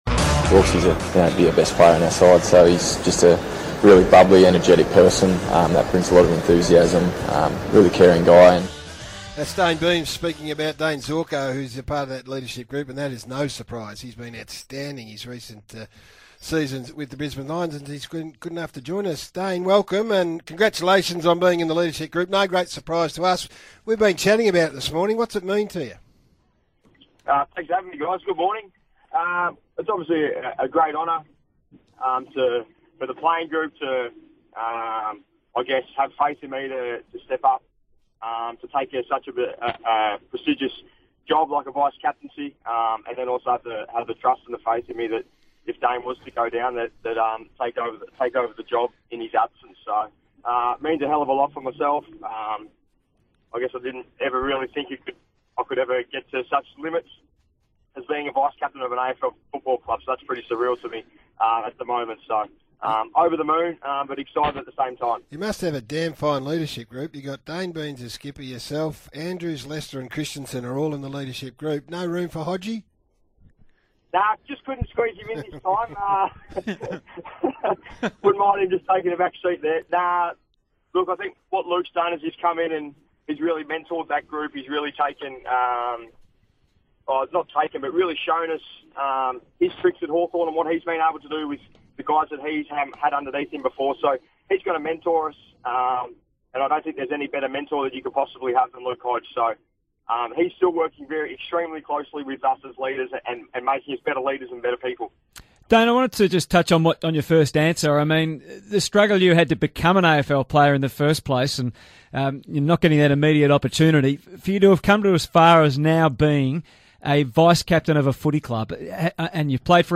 2018 Vice-Captain Dayne Zorko sits down on SEN Breakfast to discuss the newly appointed leadership group and Hodge's role as a mentor in 2018.